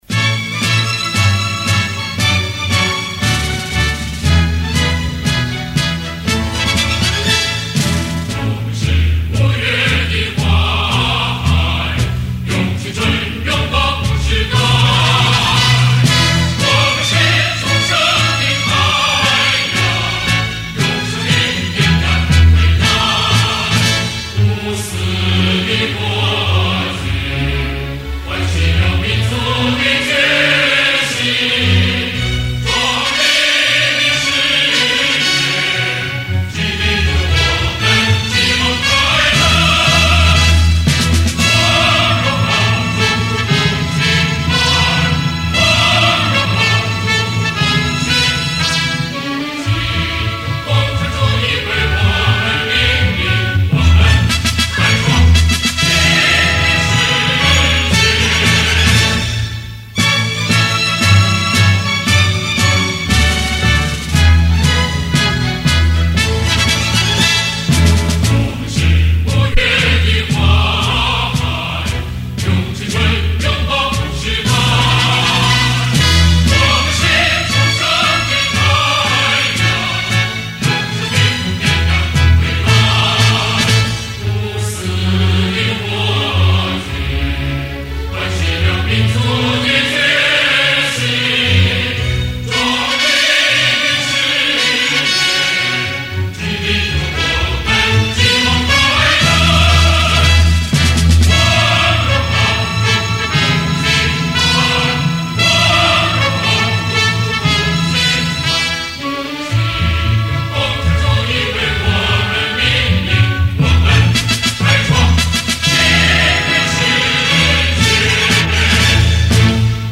标准国歌、团歌、选举背景音乐